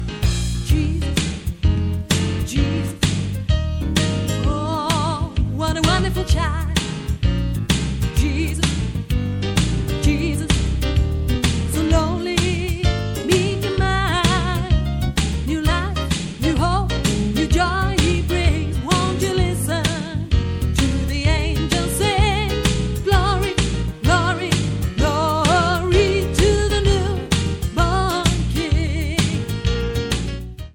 från en stäm-kassett fr förra årtusendet ;-) ingår i "Gospelmedley - Christmas"
Jesus_what_a_wonderful_child-Alt.mp3